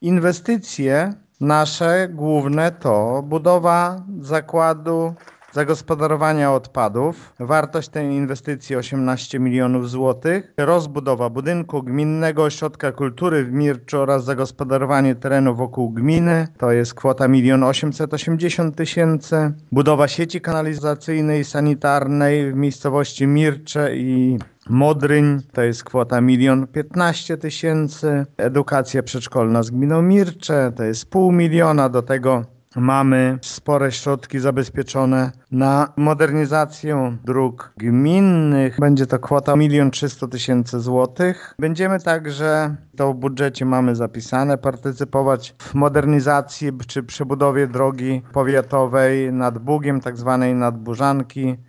- To rekordowy budżet w historii naszej gminy i rekordowe inwestycje, które chcemy zrealizować - mówi Informacyjnej Agencji Samorządowej wójt Lech Szopiński: